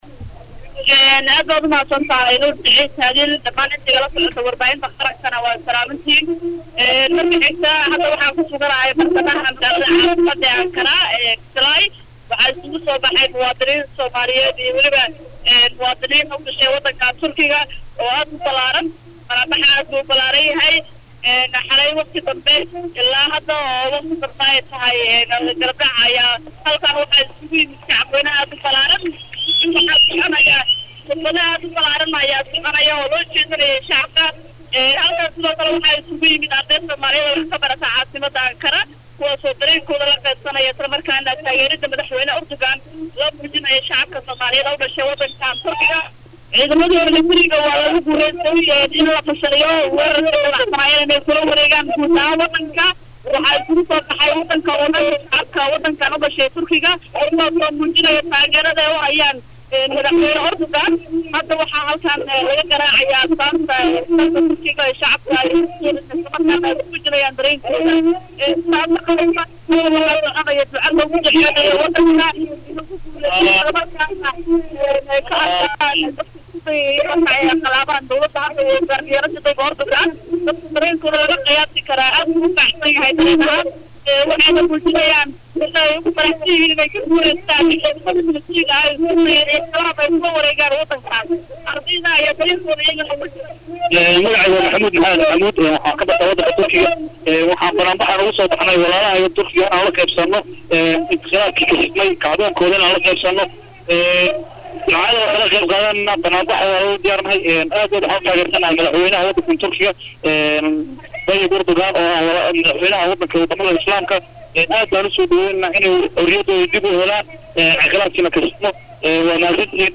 Qaar ka mid ah jaaliyadda Soomaaliyeed oo la hadlay Radio Muqdisho iyagoo ku sugan fagaaraha dibadbaxu ka dhacayay ayaa sheegay in shacabka Turkiga ay la wadaagayaan dareenkooda ku aaddan madaxweyne Erdugan.
Halkan ka dhageyso wareysiga jaaliyadda Soomaaliyeed ee Turkiga